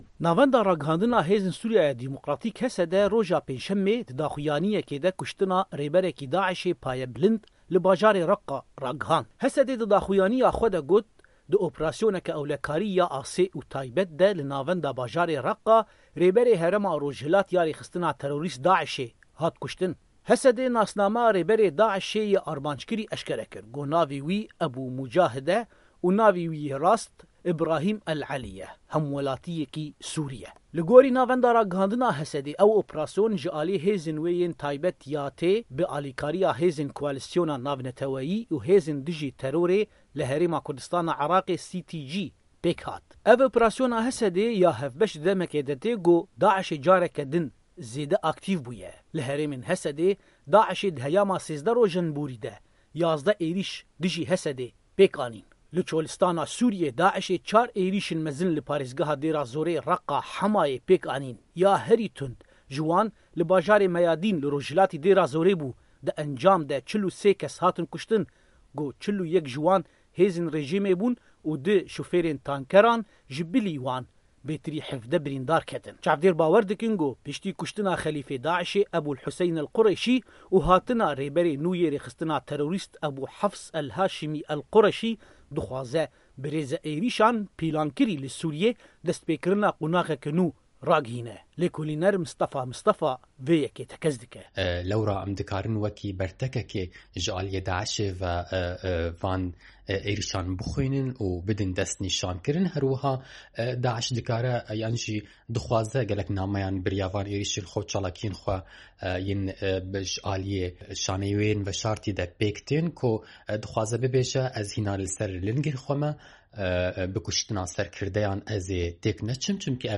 Raport_Daiş aktîv dibe(1).wav